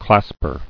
[clasp·er]